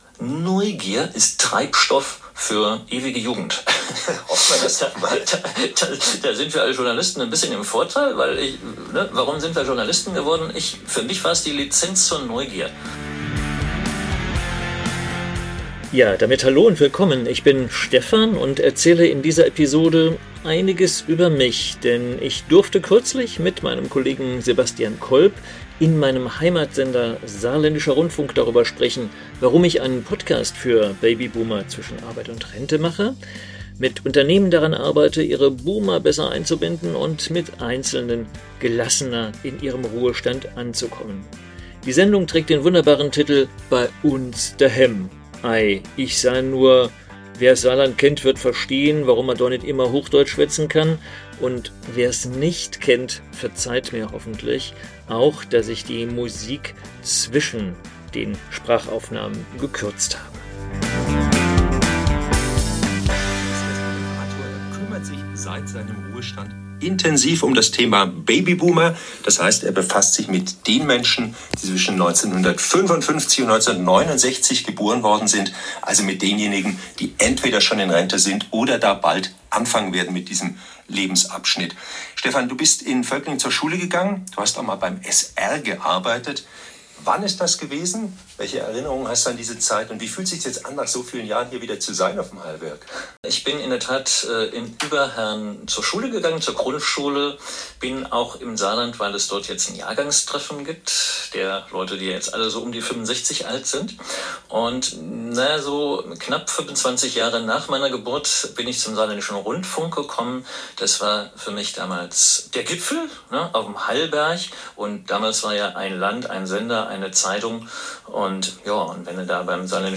"Bei uns dehemm" spricht man einen unverkennbaren Dialekt. Die gleichnamige Sendung des Saarländischen Rundfunks stellt es ihren Gästen frei, hochdeutsch oder Platt zu reden. Es geht um Menschen und Musik aus der Region.